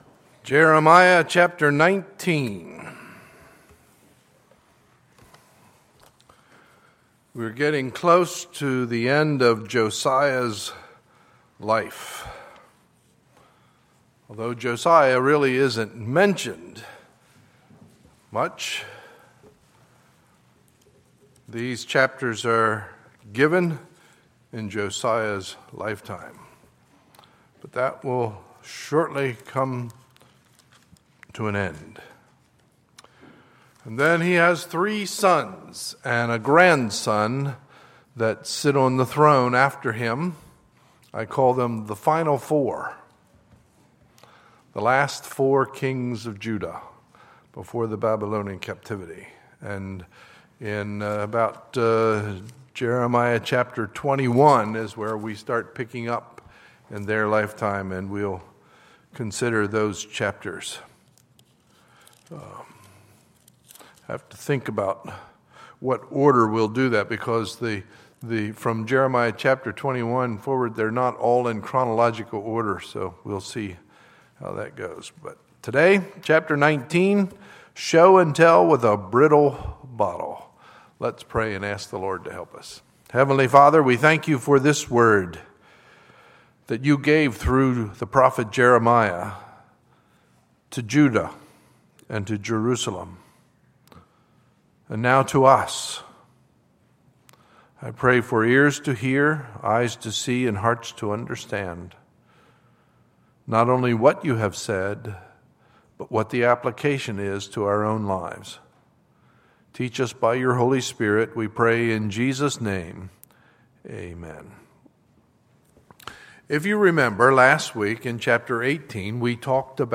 Sunday, June 14, 2015 – Sunday Morning Service